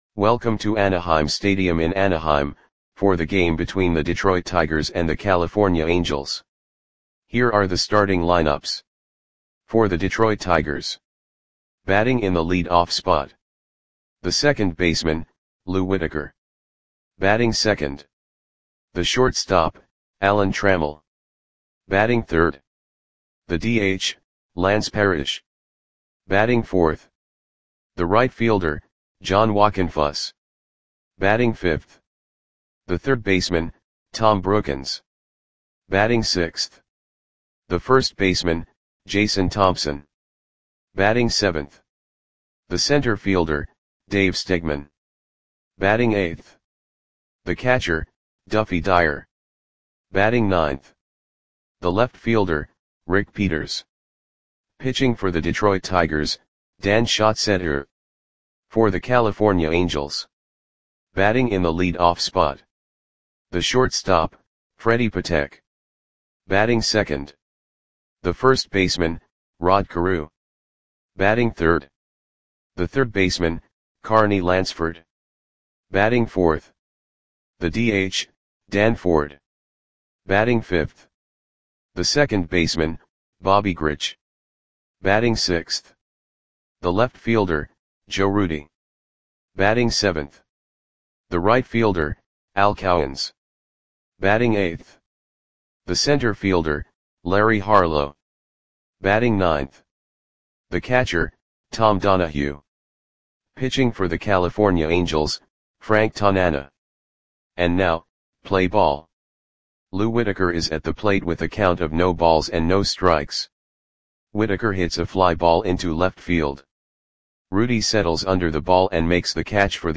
Audio Play-by-Play for California Angels on May 10, 1980
Click the button below to listen to the audio play-by-play.